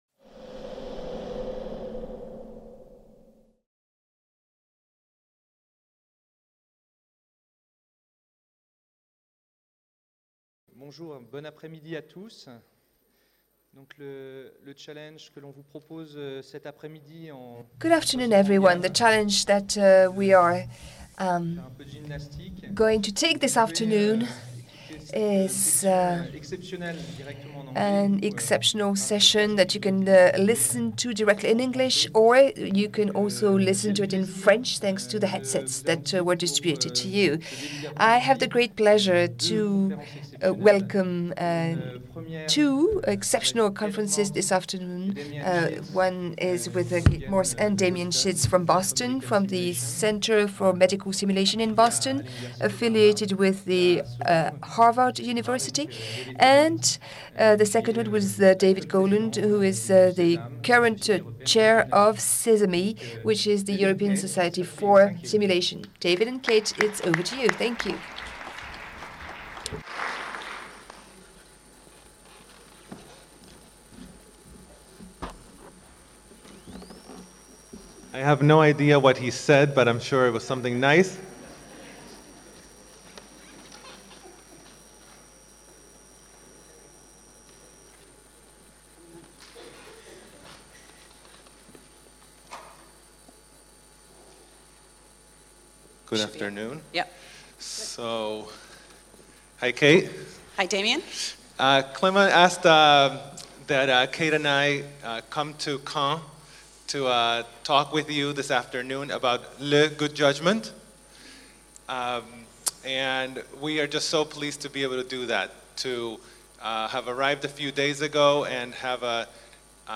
SOFRASIMS 2018 | 08 - « The good judgement » (version anglaise) | Canal U